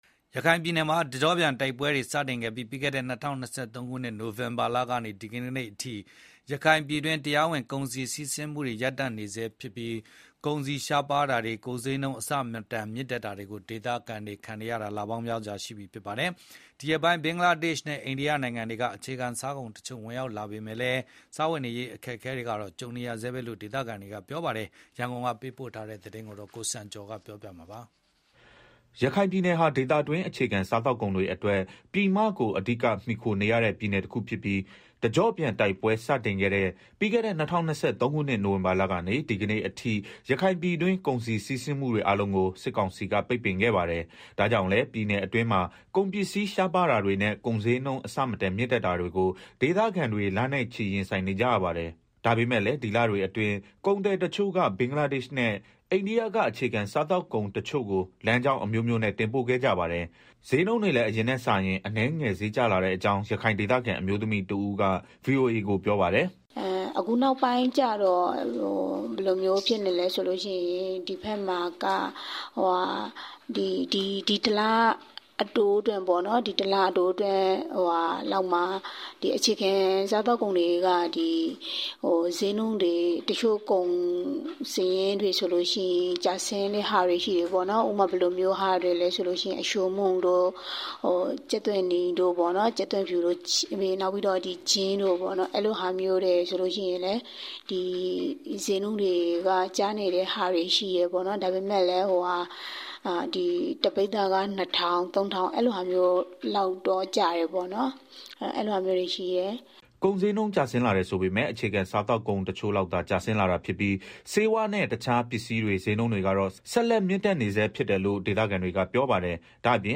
ရခိုင်ပြည်နယ်မှာ တကျော့ပြန်တိုက်ပွဲတွေ စတင်ခဲ့တဲ့ ပြီးခဲ့တဲ့ ၂၀၂၃ ခုနှစ် နိုင်ဝင်ဘာကနေ ဒီကနေ့အထိ ရခိုင်ပြည်တွင်း တရားဝင်ကုန်စည်စီးဆင်းမှုတွေ ရပ်တန့်နေဆဲဖြစ်ပြီး ကုန်ပစ္စည်းရှားပါး တာတွေနဲ့ ဈေး နှုန်းအဆမတန်မြင့်တက်တာတွေကို ဒေသခံတွေအနေနဲ့ လပေါင်းများစွာ ရင်ဆိုင်နေရပါတယ်။ ဒီရက်ပိုင်း ဘင်္ဂလားဒေ့ရှ်နဲ့ အိန္ဒိယနိုင်ငံတွေက ကုန်ပစ္စည်းတချို့ ဝင်လာတဲ့အတွက် အခြေခံစားကုန်တချို့ ရောက်လာပေမဲ့ စားဝတ်နေရေး အခက်အခဲတွေတော့ ကြုံနေရဆဲပဲလို့ ဒေသခံ တွေ ကပြောပါတယ်။ ရခိုင်ပြည်တွင်း ကုန်စည်စီးဆင်းမှု အခြေအနေကို ရန်ကုန်က သတင်းပေးပို့ထားပါတယ်။
ဒါပေမဲ့လည်း ဒီလတွေအတွင်း ကုန်သည်တချို့က ဘင်္ဂလားဒေ့ရှ်နဲ့ အိန္ဒိယက အခြေခံစားကုန်တချို့ကို လမ်းကြောင်းအမျိုးမျိုးနဲ့ တင်ပို့ခဲ့ကြပါတယ်။ ဈေးနှုန်းတွေလည်း အရင်နဲ့စာရင် အနည်းငယ် ဈေးကျလာတဲ့ကြောင်း ရခိုင်ဒေသခံ အမျိုးသမီးတဦး VOA ကို ပြောပါတယ်။
ရခိုင်ပြည်နယ်အတွက် လိုအပ်တဲ့ အခြေခံစားသောက်ကုန်တွေကို ရခိုင်ပြည်နယ်နဲ့ အိမ်နီးချင်းနိုင်ငံတွေဖြစ်တဲ့ အိန္ဒိယနဲ့ ဘင်္ဂလားဒေ့ရှ်နိုင်ငံတွေကနေ ခက်ခက်ခဲခဲ တင်သွင်းနေရဆဲ ဖြစ်တဲ့အကြောင်း ရခိုင်ကုန်သည်တယောက်က VOA ကို အခုလို ပြောပါတယ်။